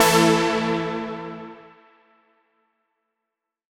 Index of /musicradar/future-rave-samples/Poly Chord Hits/Straight
FR_SARP[hit]-A.wav